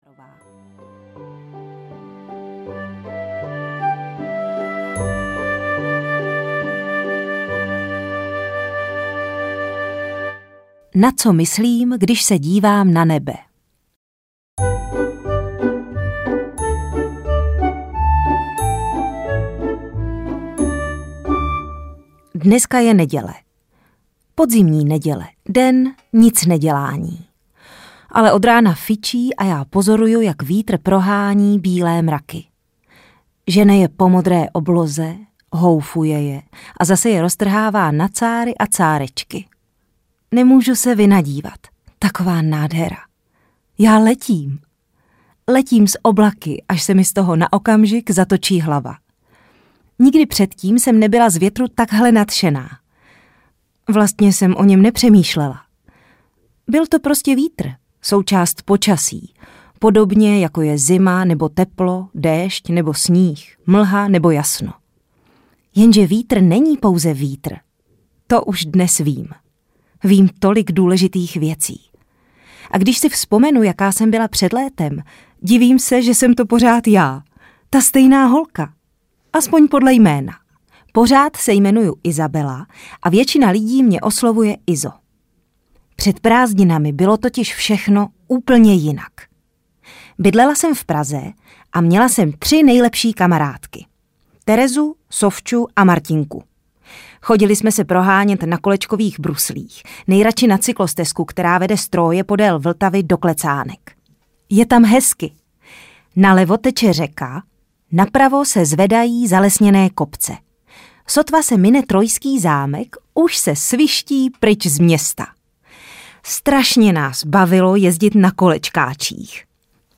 Isabela a bílé mraky audiokniha
Ukázka z knihy
• InterpretZuzana Kajnarová